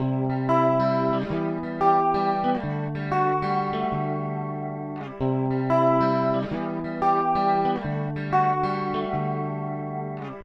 1. Chorus
El Chorus es un efecto que modula ligeramente la afinación o tono de la guitarra (la desafina) y le cambia el tiempo (la atrasa).
Le agrega un color muy lindo al tono de la guitarra, que se lo puede describir como dulce y cálido.
guitarra-con-chorus_tyghn4.ogg